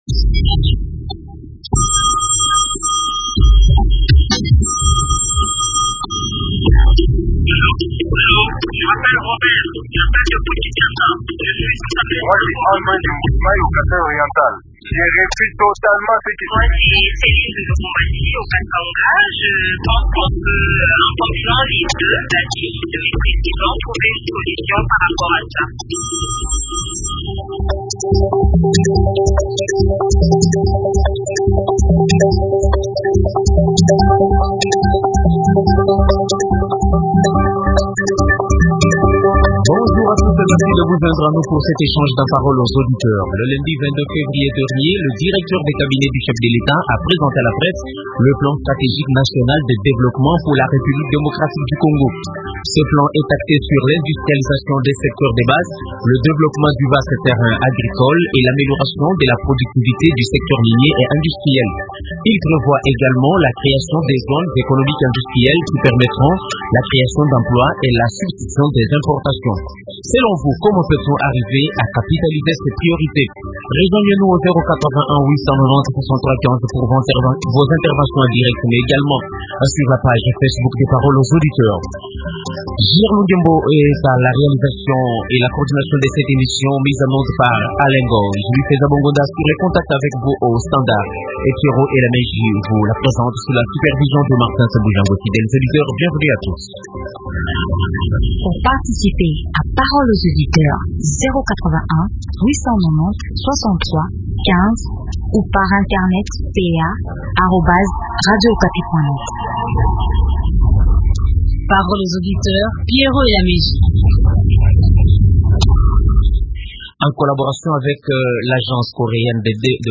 analyste économique et stratège.